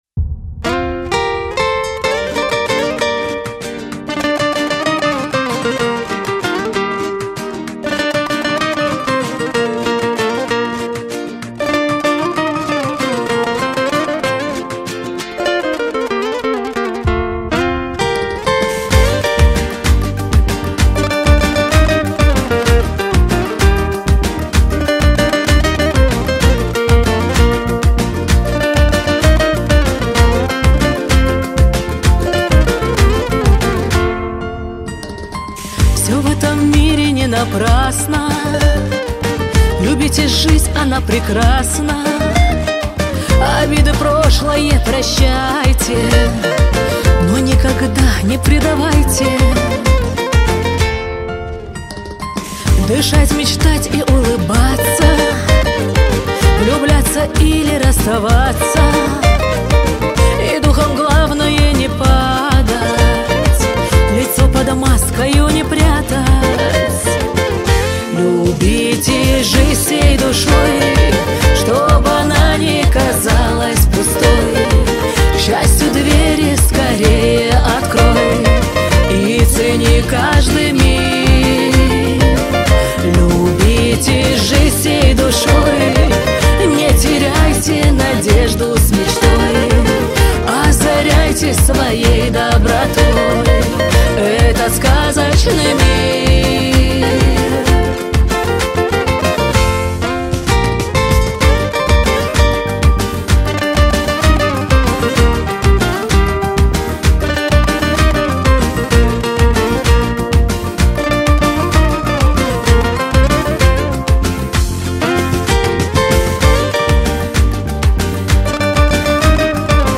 • Категория: Русская музыка